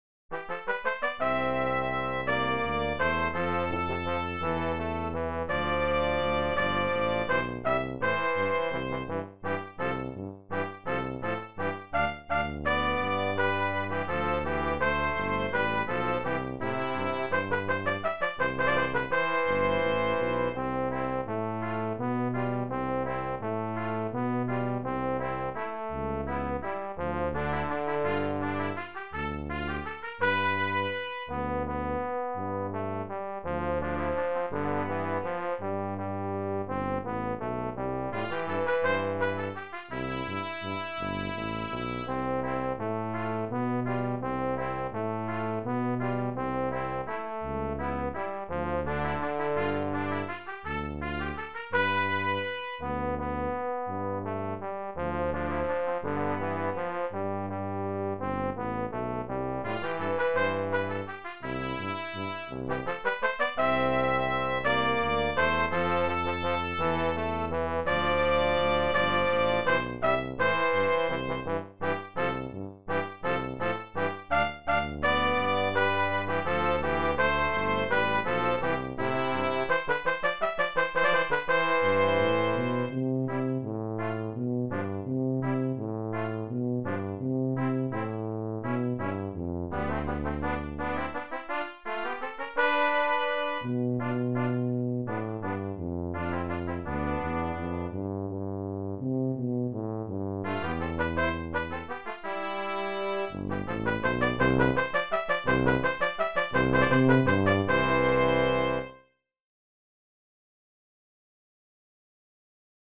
Brass Trio TTT
Listen to a synthesized version (minus the repeat and DS).